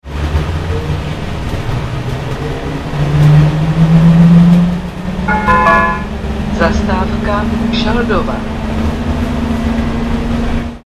Trolejbusy totiž disponují přehlášenými zastávkami a dalšími zvukovými sekvencemi.
Zásadním problémem je však to, že při odjezdu ze zastávek se nehlásí standardní "Příští zastávka", ale pouze "Zastávka".
- Ukázku vyhlášení příští zastávky (Šaldova) si